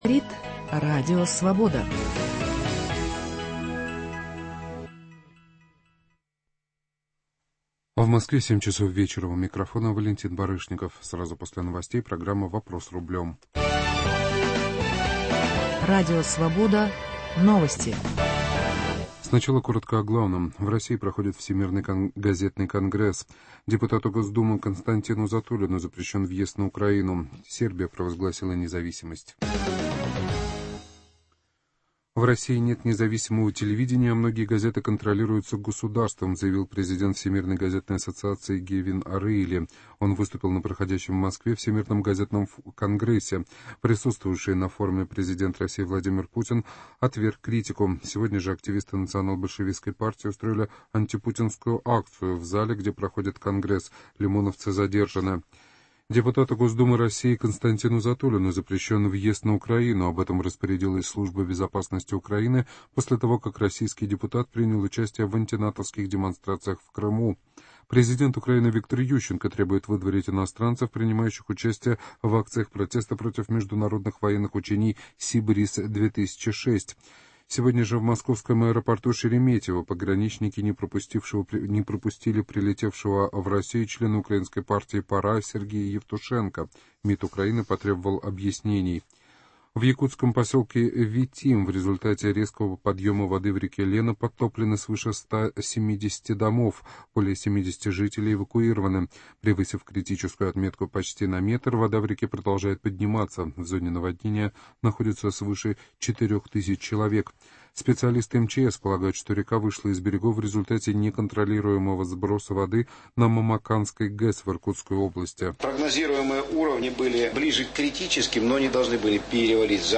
Михаил Геннадьевич Делягин - экономист и политолог